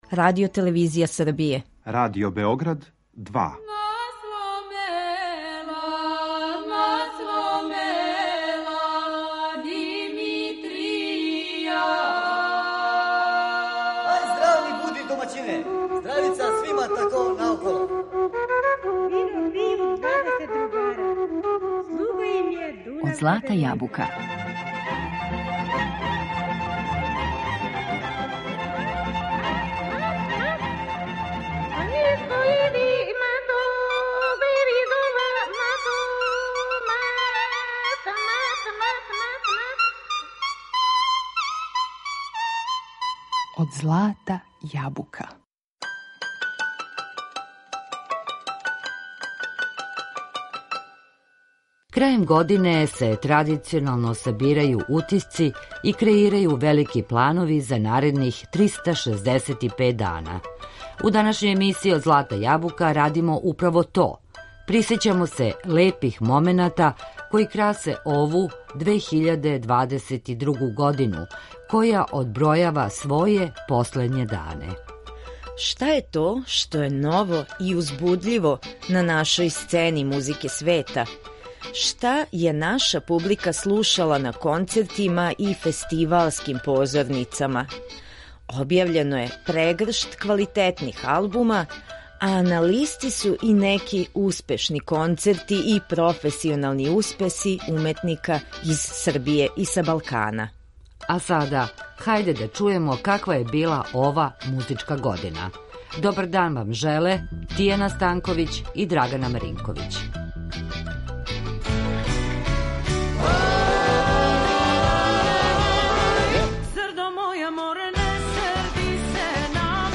севдалинку